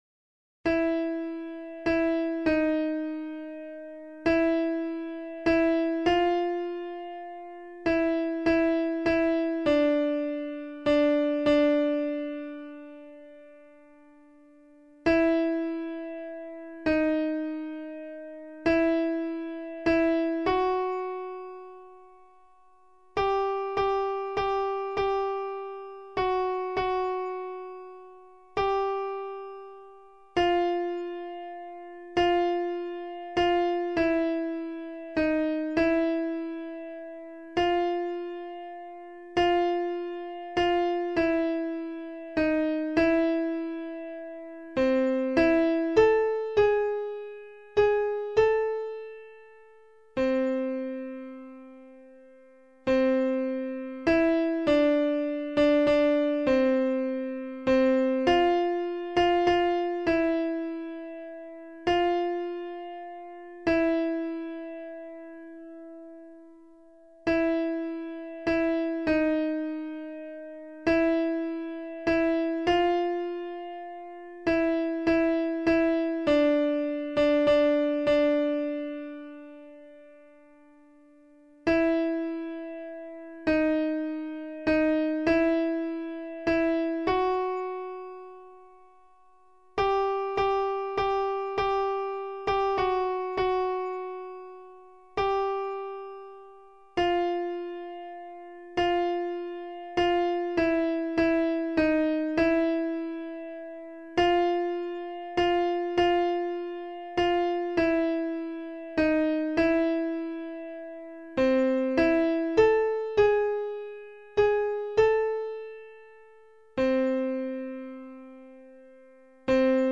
Information and practice vocal scores & mp3's for the